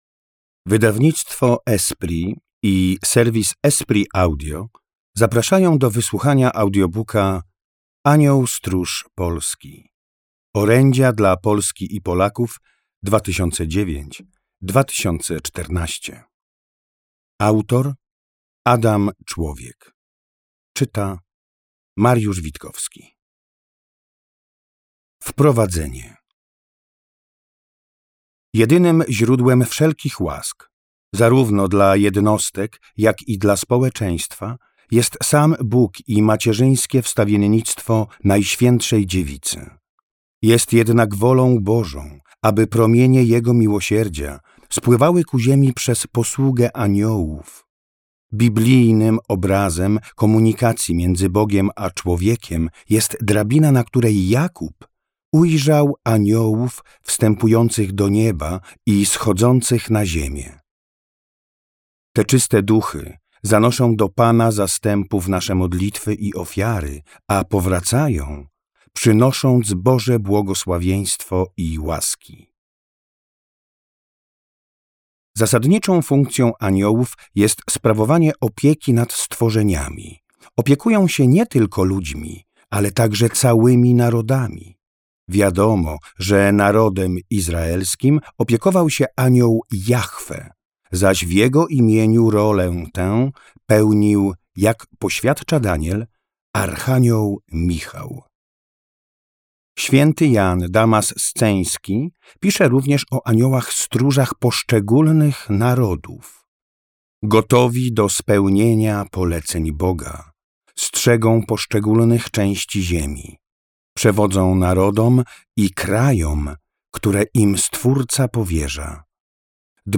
Anioł Stróż Polski – Audiobook